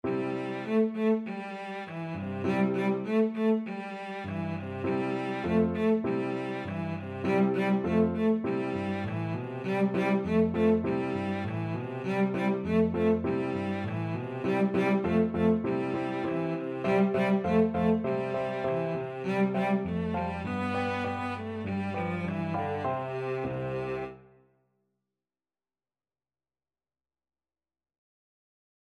Cello
4/4 (View more 4/4 Music)
C4-B4
Allegro moderato (View more music marked Allegro)
C major (Sounding Pitch) (View more C major Music for Cello )